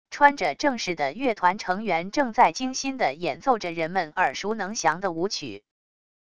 穿着正式的乐团成员正在精心的演奏着人们耳熟能详的舞曲wav音频